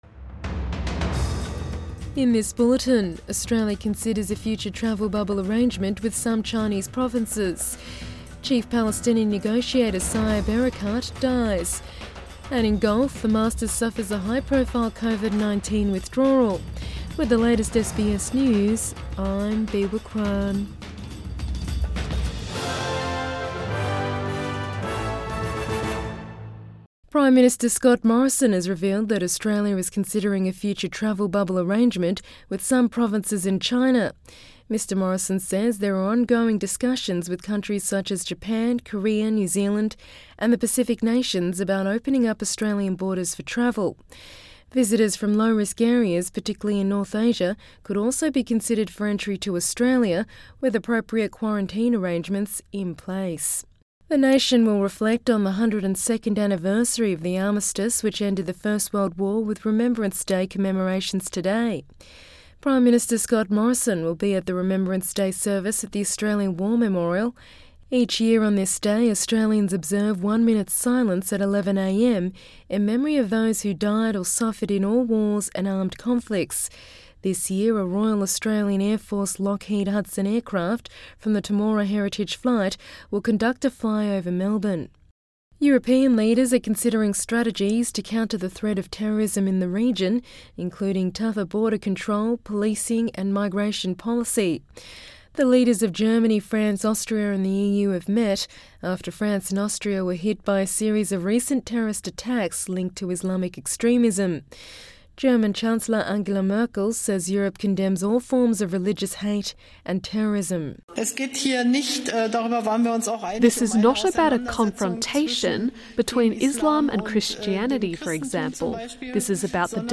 AM bulletin 11 November 2020